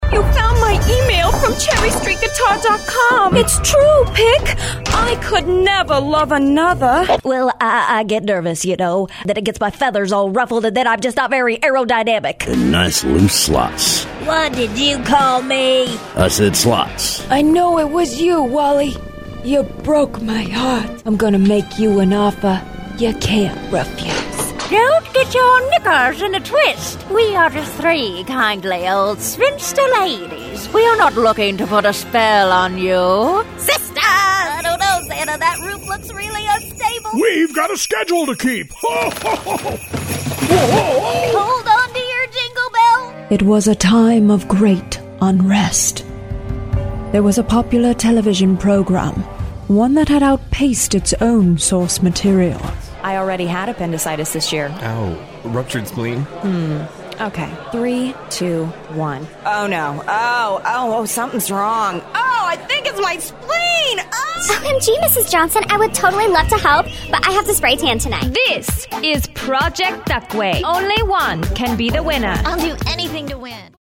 Female
I can come across as quirky/ animated when needed, but also lend my voice to professional and polished reads.
Character / Cartoon
Character Voices Demo